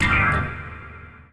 Misc Synth stab 02.wav